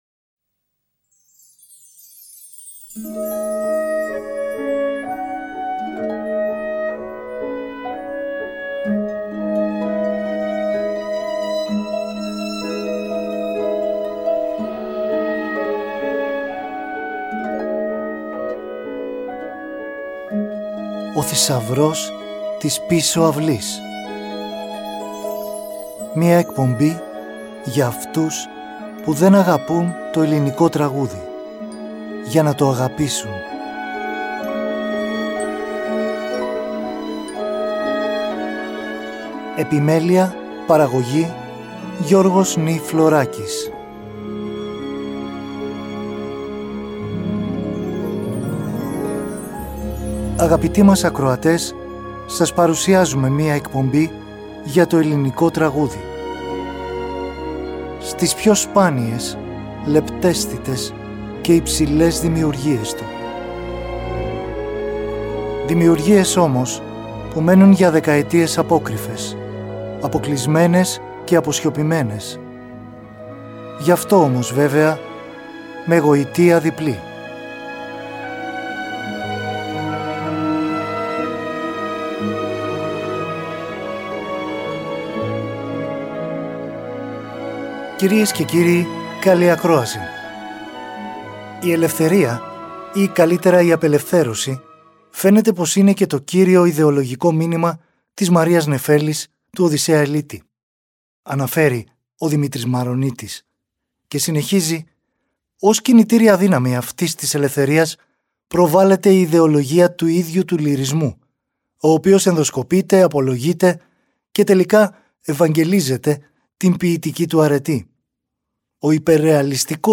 μουσική και τραγούδια
Ελληνικη Μουσικη